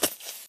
creeper3.ogg